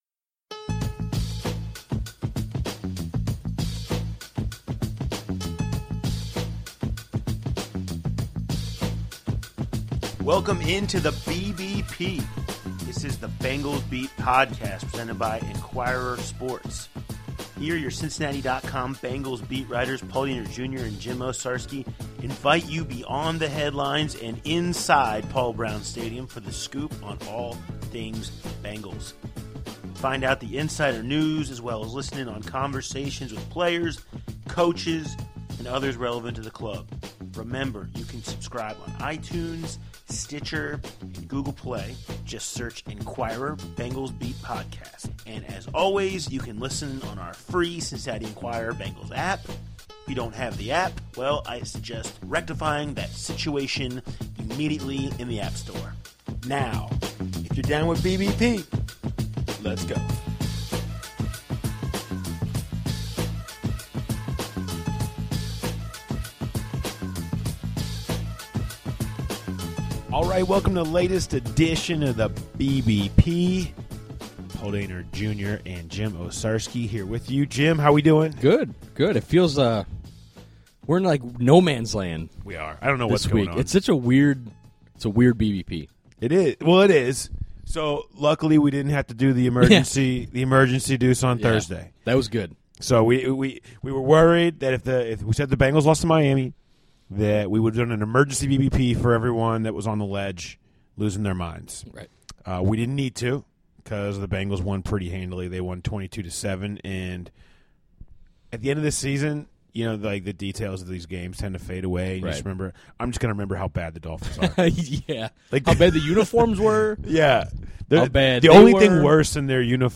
Hear from H-back Ryan Hewitt, running back Jeremy Hill and Burfict.